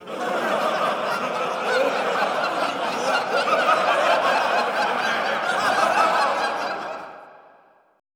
LAUGHTER 2-L.wav